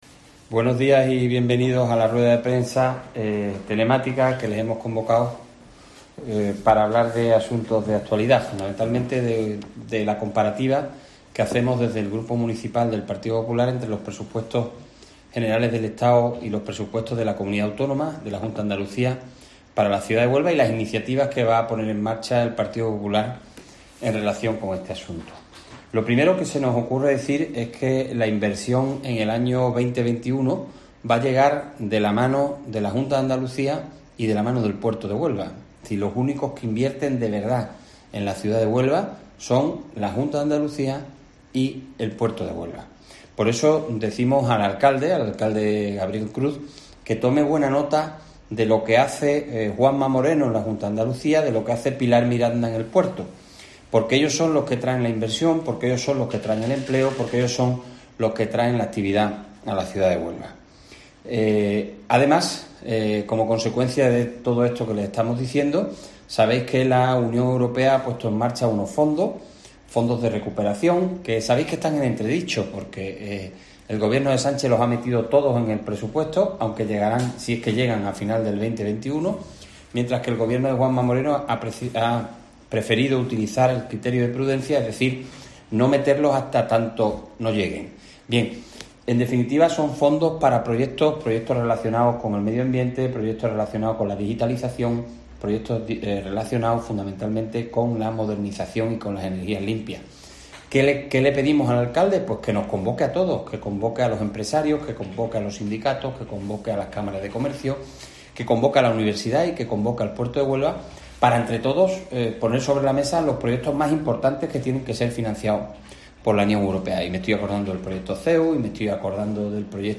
Jaime Pérez, portavoz del Grupo Popular en el Ayuntamiento de Huelva